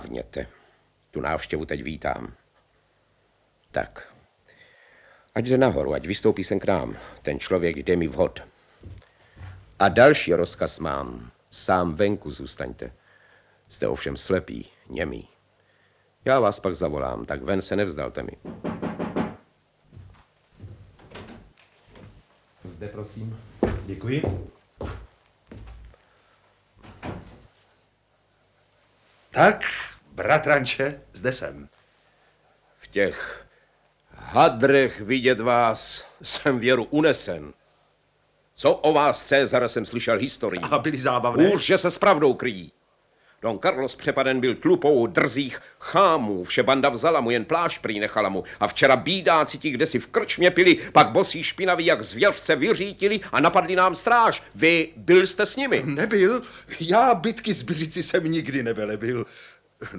Audiobook
Read: Otakar Brousek